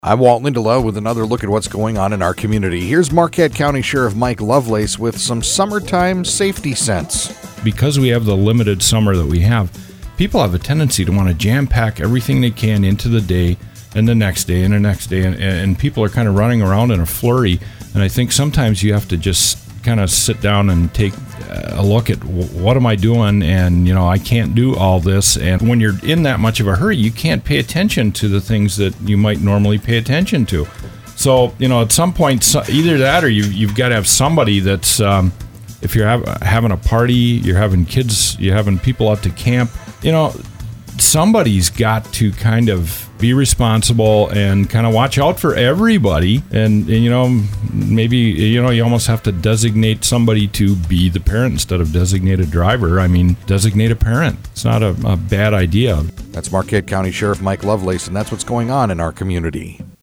Click HERE To Listen To Interview as Mp3 Click To Submit Press Releases, News, Calendar Items, and Community Events to mediaBrew radio stations WFXD, WKQS, WRUP, GTO, Fox Sport Marquette, and 106.1 The...